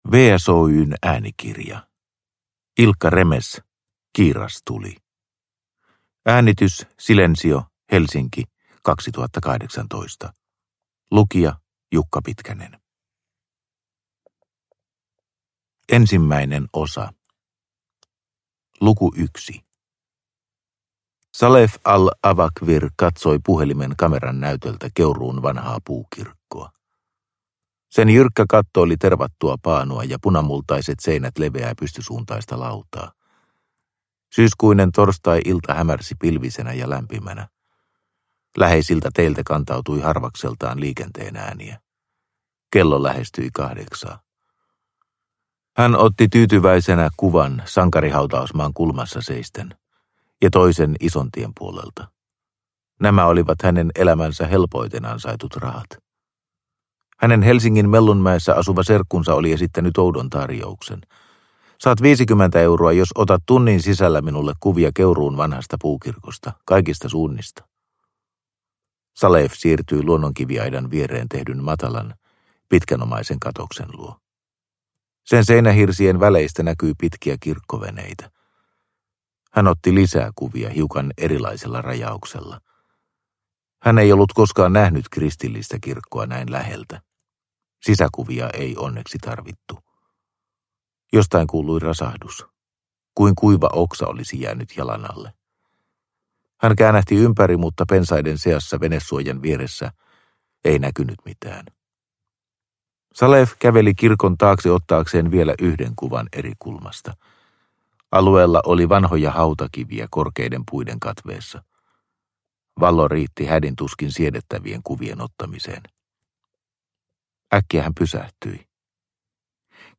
Kiirastuli – Ljudbok – Laddas ner